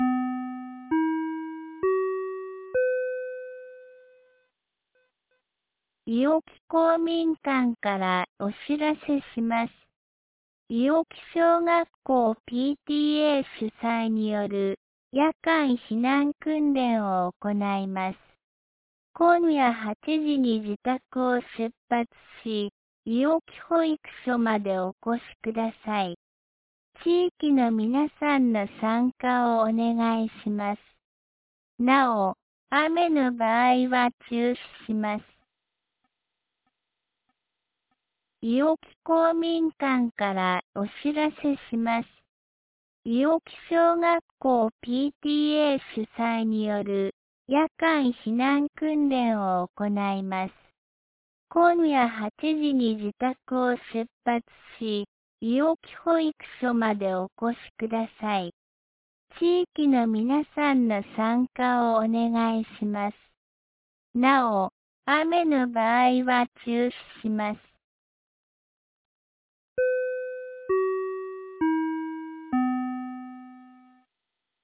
2025年10月25日 17時11分に、安芸市より伊尾木へ放送がありました。
放送音声